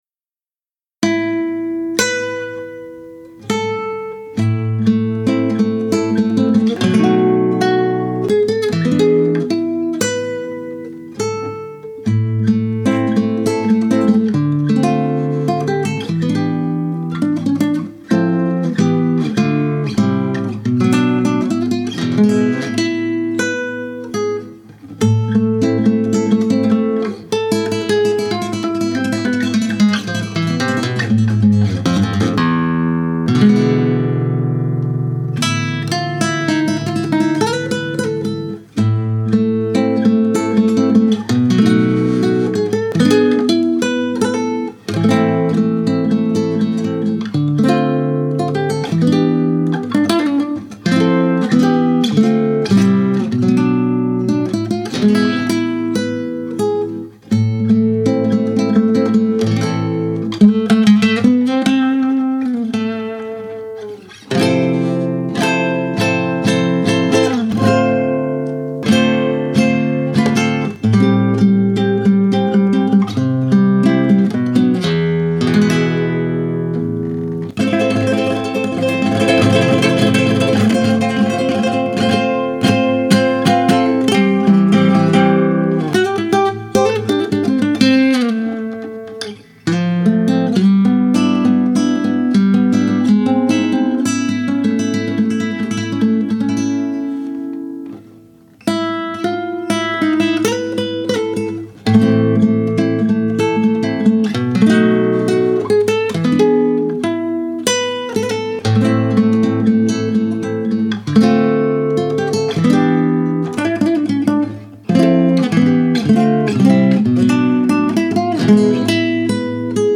musical duo featuring vocalist/keyboardist